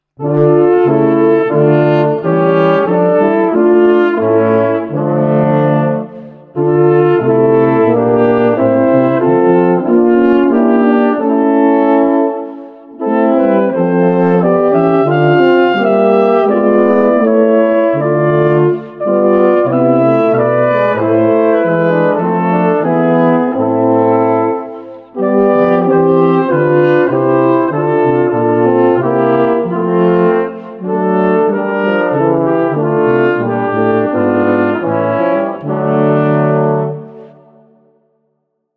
Barocke Kirchenmusik für Blechblasinstrumente
2x Flügelhorn, F-B-Horn, Es-Tuba [0:38]